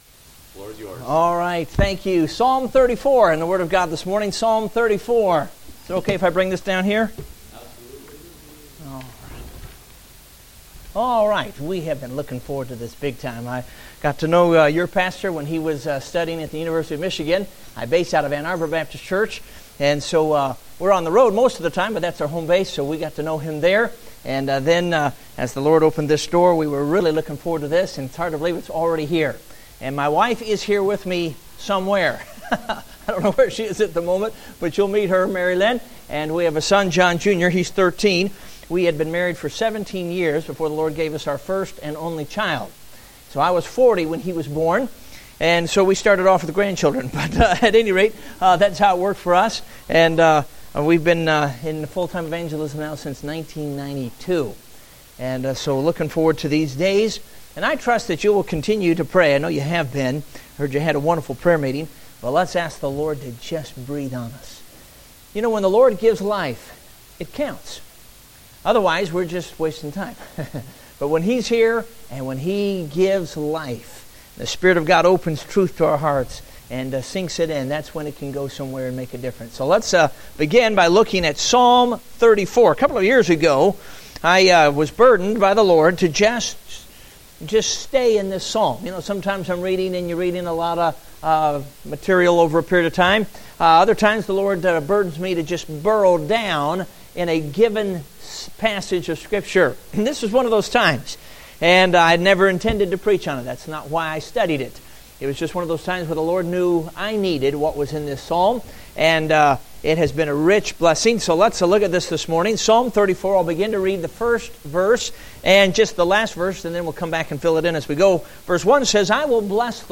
Date: September 6, 2015 (Adult Sunday School)